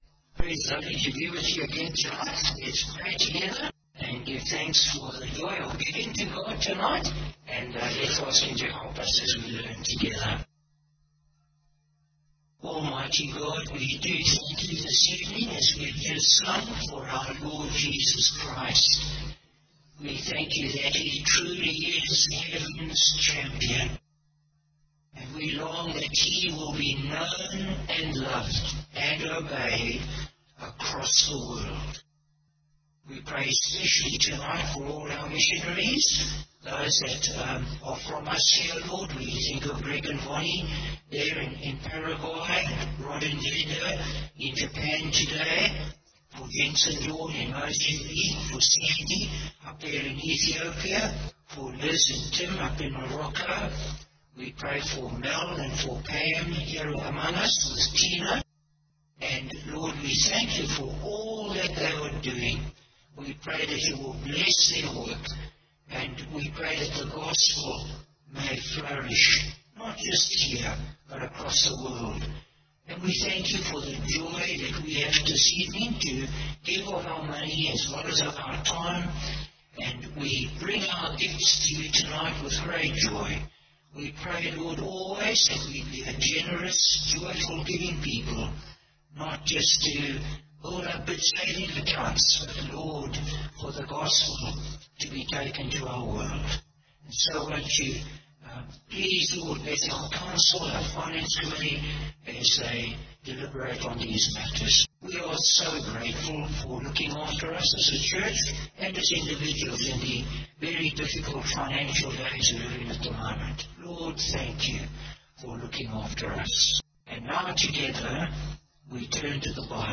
Bible Text: 2 Peter 1: 7-8 | Preacher: Bishop Warwick Cole-Edwards | Series: 2 Peter